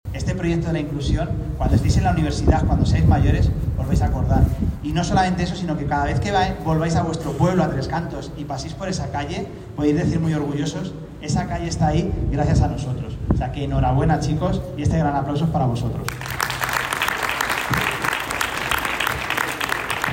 ¡Enhorabuena chicos!”, rompiendo en un gran aplauso por todos los presentes y dibujándose una gran sonrisa de emoción en los alumnos y alumnas.